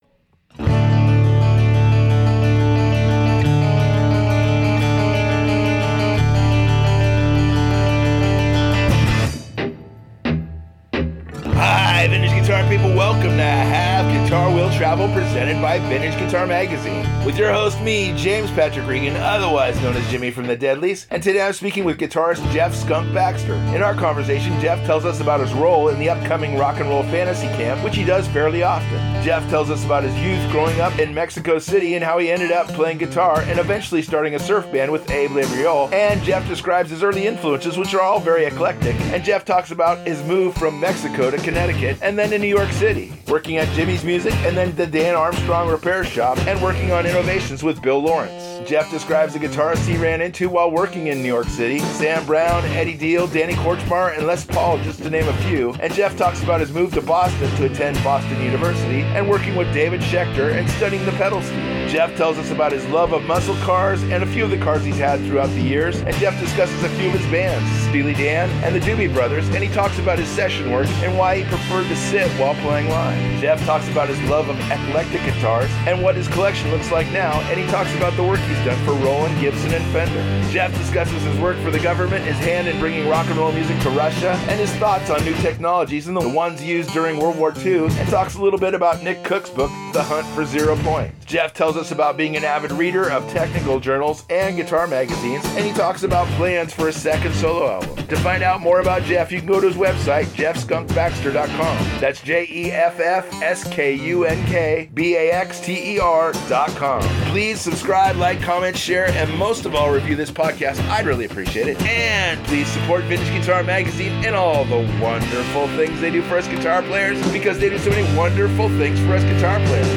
speaks with guitarist Jeff “Skunk” Baxter